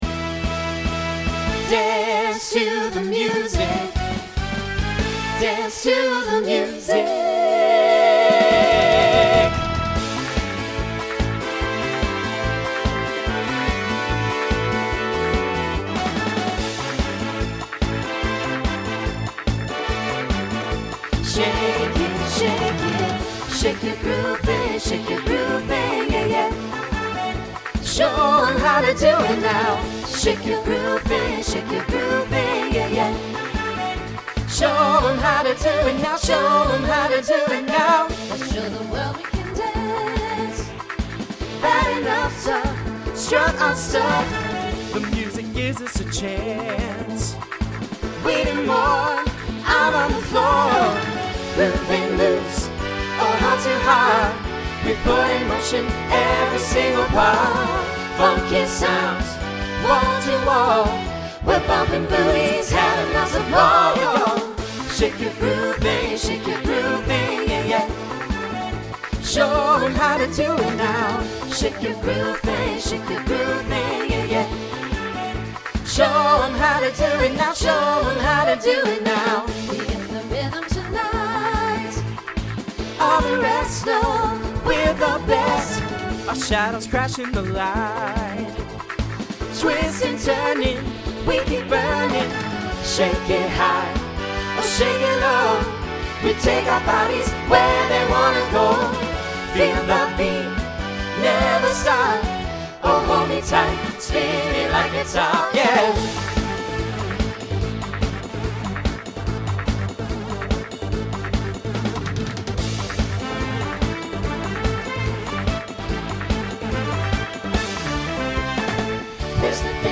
Voicing SATB Instrumental combo Genre Disco , Pop/Dance